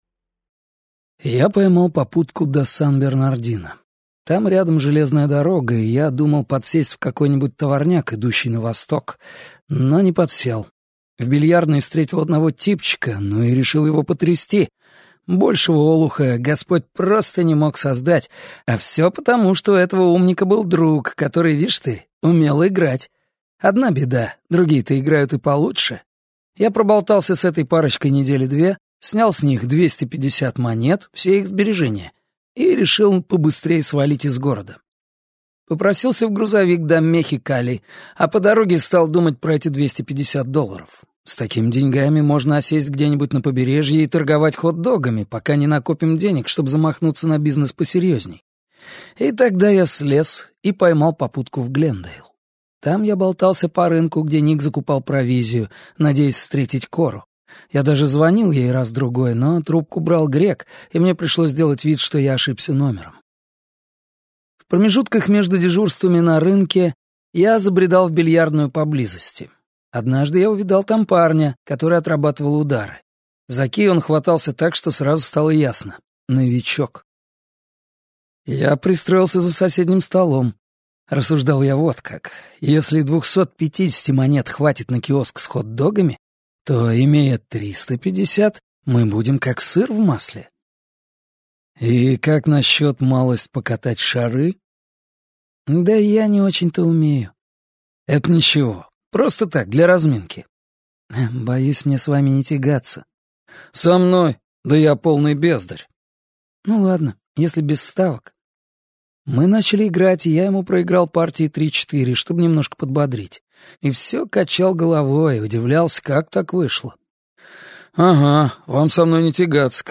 Аудиокнига Почтальон всегда звонит дважды | Библиотека аудиокниг